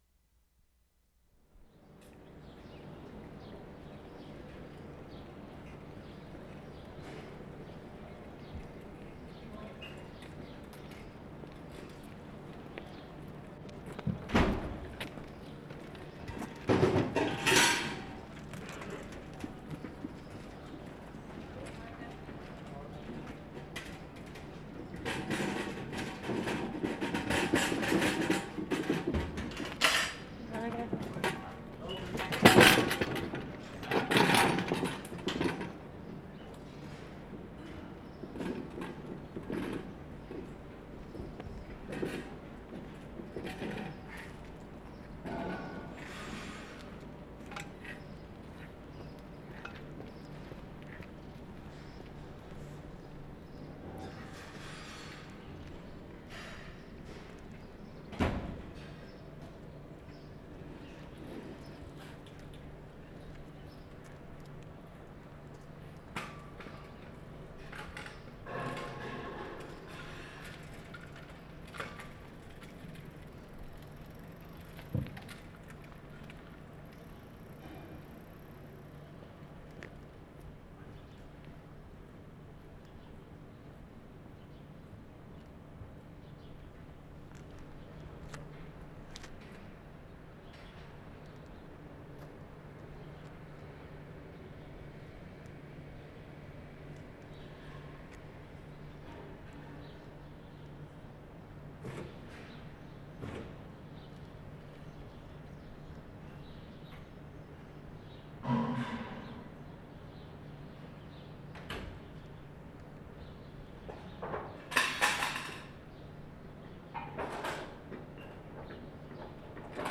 Bissingen, Germany March 4/75
MILK CARTS AND CANS on Mittlere Strasse, at 7:20 am
mark * cans clanking, milk delivery along the road. [0:24]
8. Milk cans clanking, a cart with two cans.